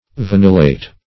vanillate - definition of vanillate - synonyms, pronunciation, spelling from Free Dictionary Search Result for " vanillate" : The Collaborative International Dictionary of English v.0.48: Vanillate \Va*nil"late\, n. (Chem.)